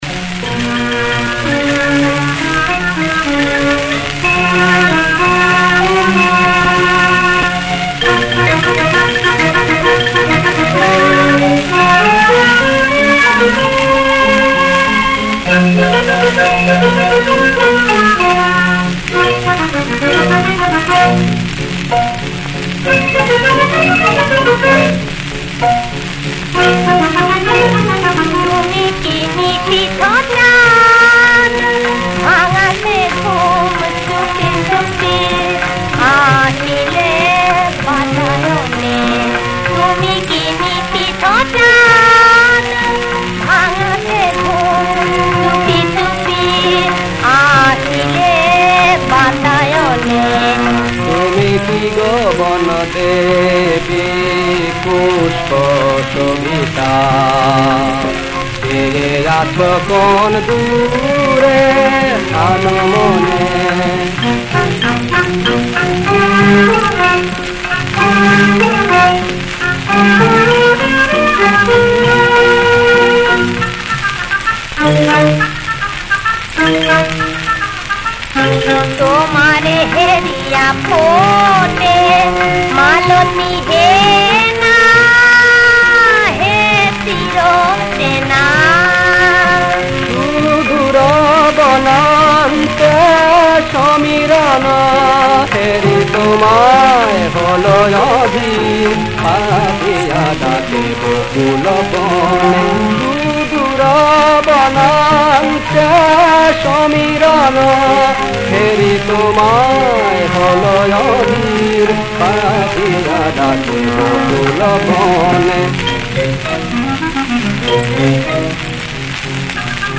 • তাল: দাদরা
• গ্রহস্বর: পা